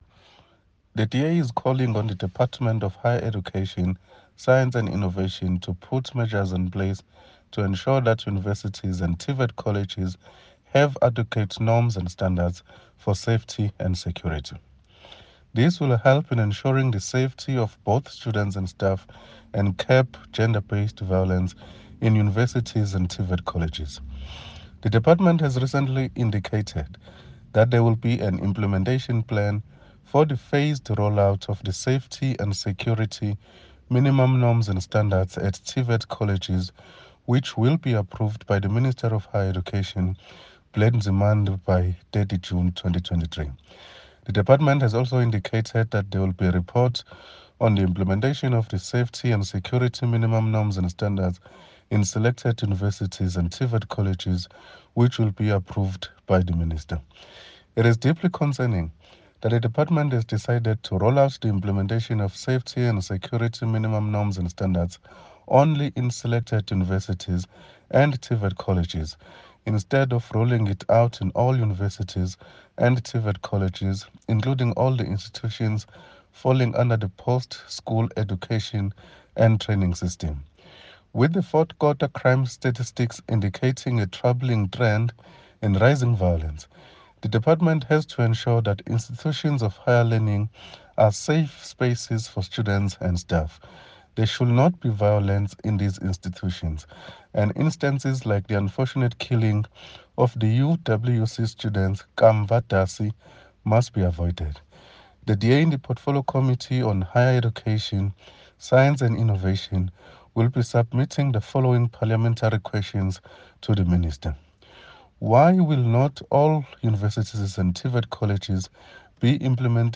soundbite by Sibongiseni Ngcobo MP